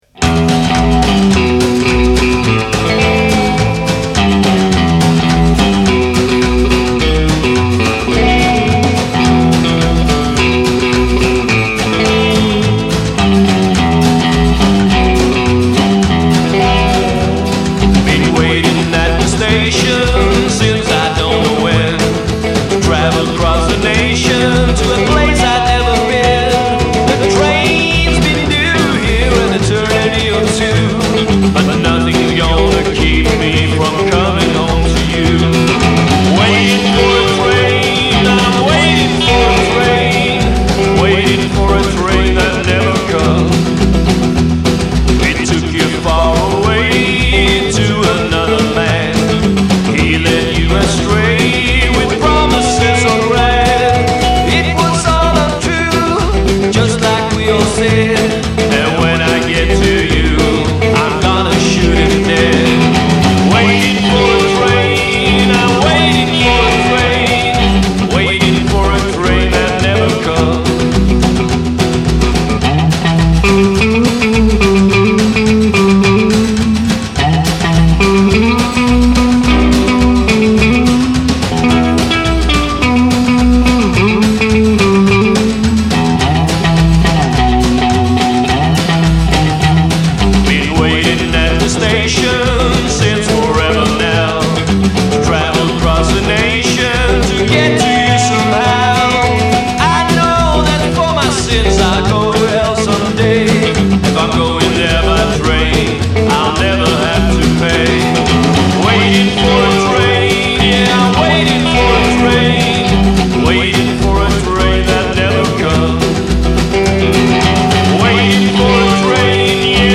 en trio